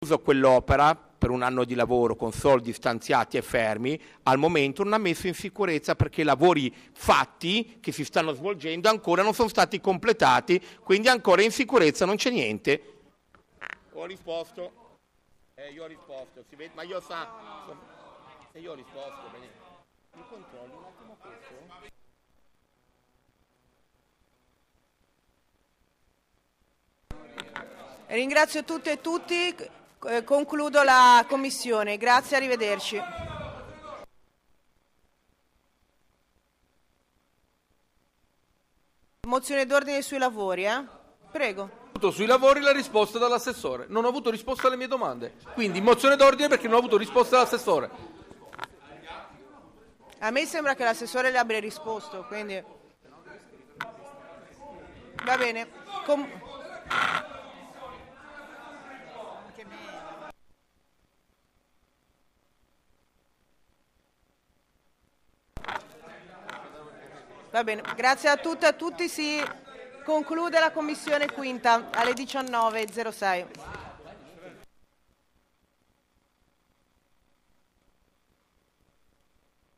Sedute del Consiglio Comunale